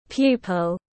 Pupil /ˈpjuː.pəl/